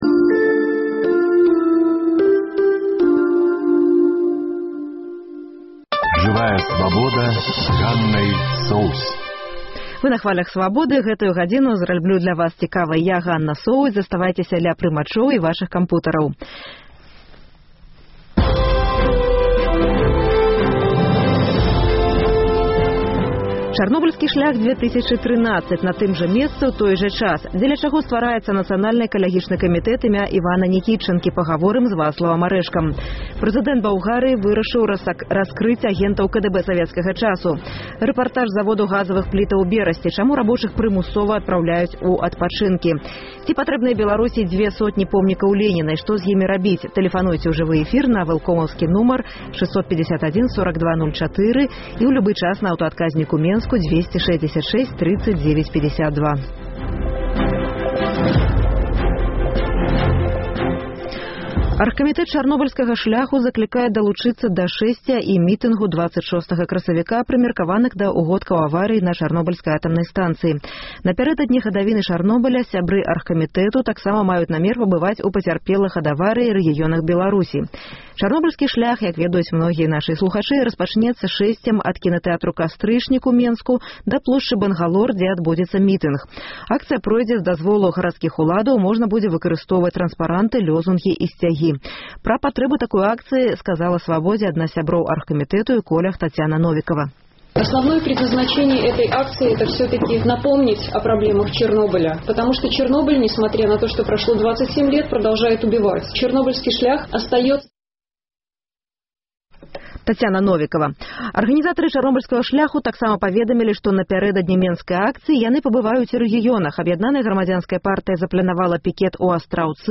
Рэпартаж з заводу «Брэстгазаапарат», дзе рабочых прымусова адпраўляюць у адпачынкі.